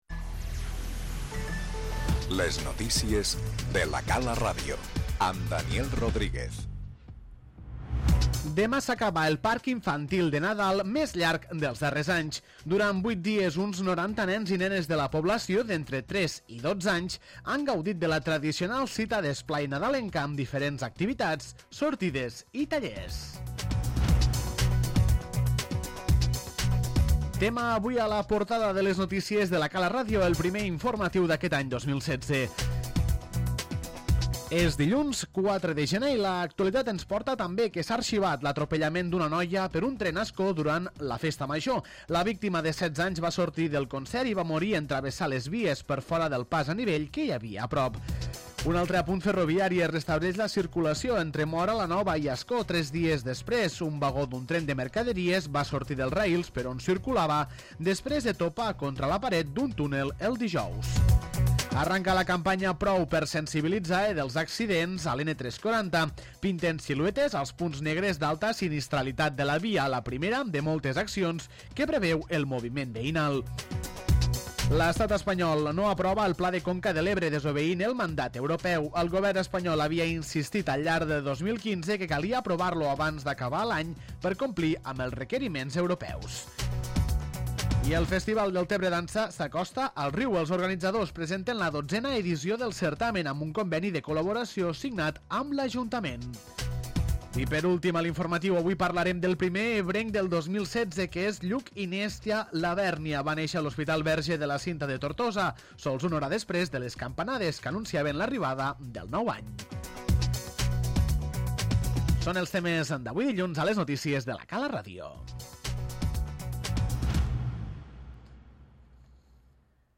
Encetem l'any amb el primer informatiu de La Cala Ràdio parlant del balanç del Parc Infantil de Nadal del municipi, així com d'altres notícies d'àmbit territorial.